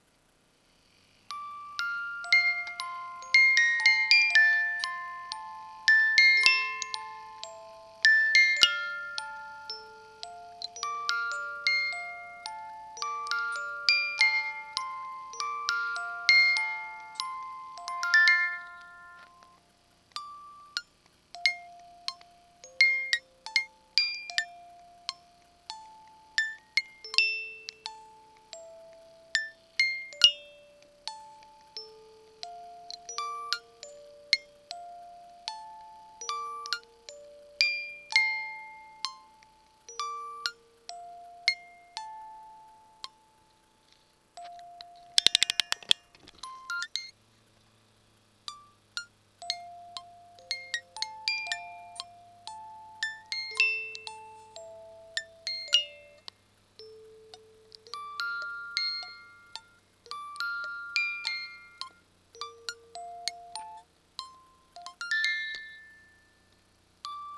creepy music box